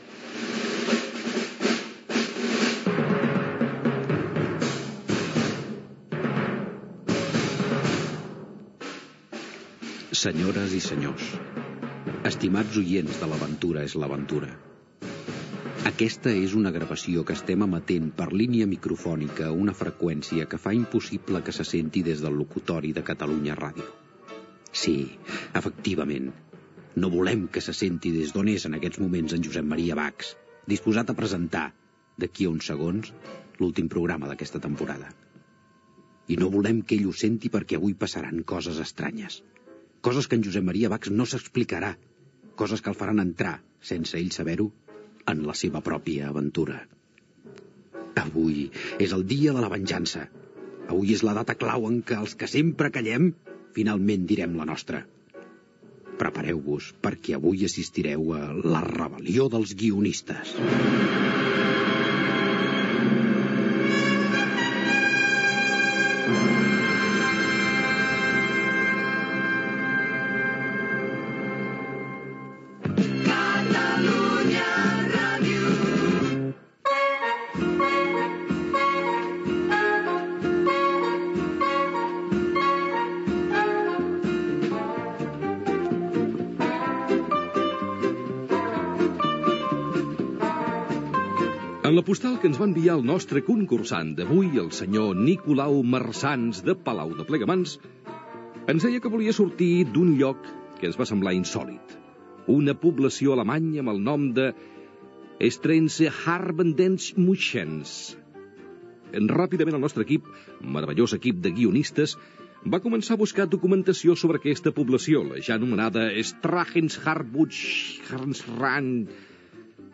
Gènere radiofònic Entreteniment